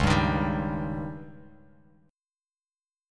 Dbsus2b5 chord